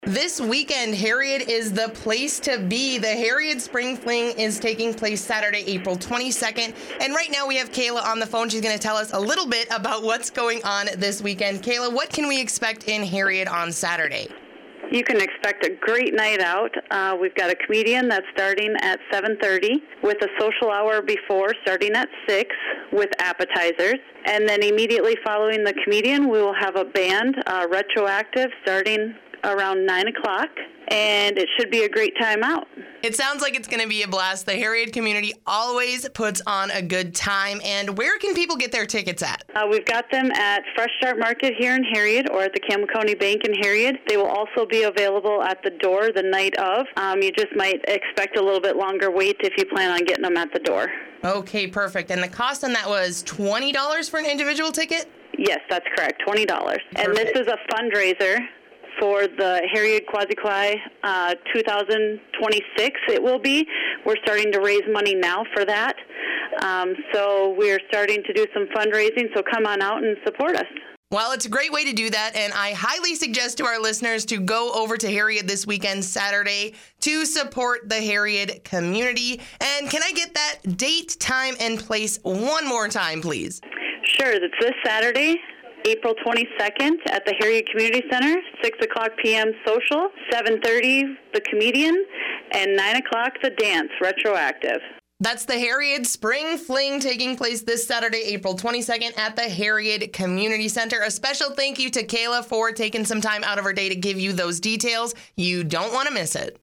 In the following interview